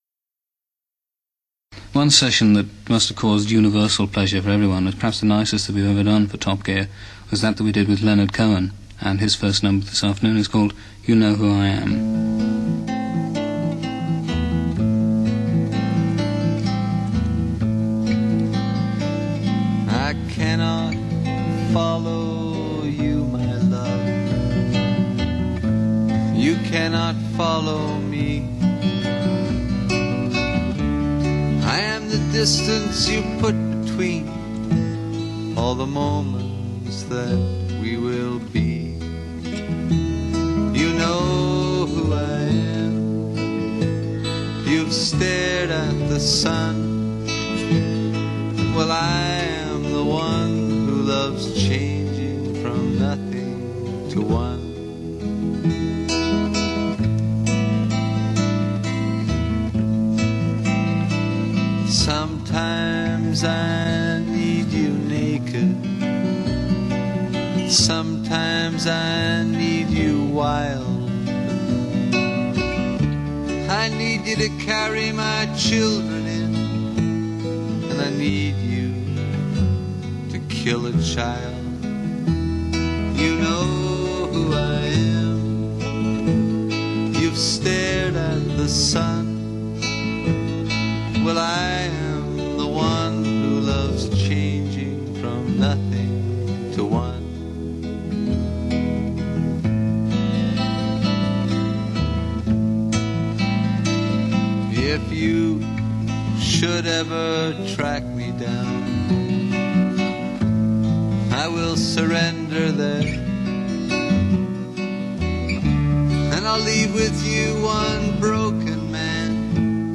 singer and guitar